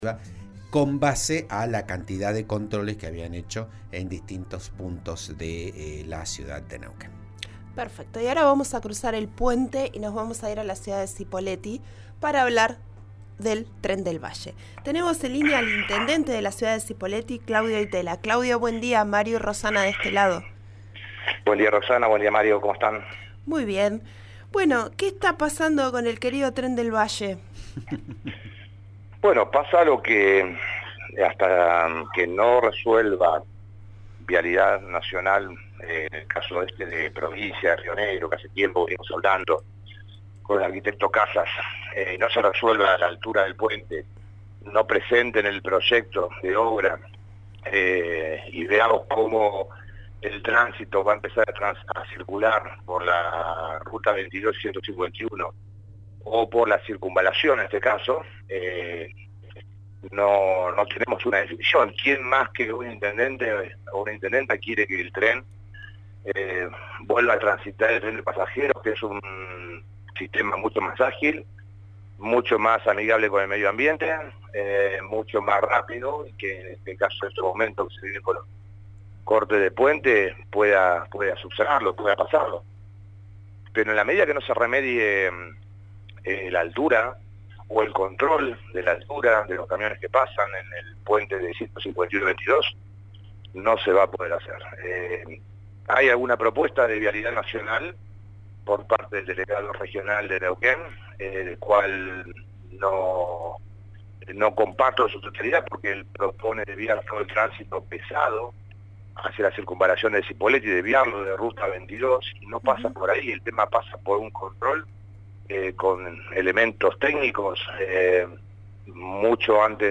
Así lo expresó esta mañana el intendente de Cipolletti, Claudio Di Tella, en declaraciones en «Vos a Diario» por RN Radio.